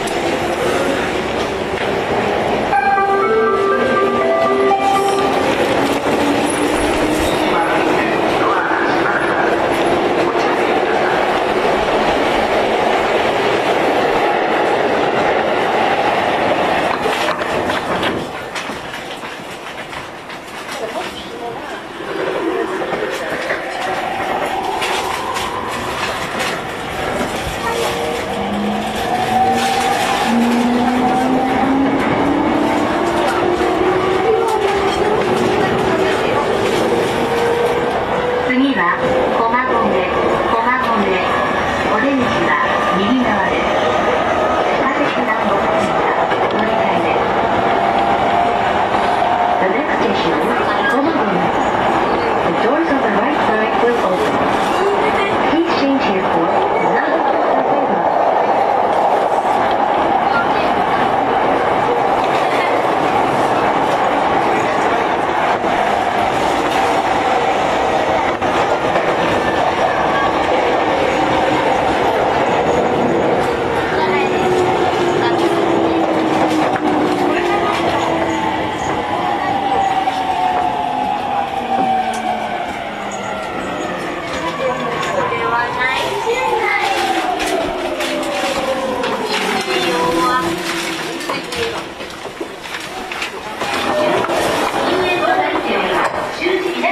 走行音
YM05 E231系 巣鴨-駒込 1:37 9/10 上の続きです。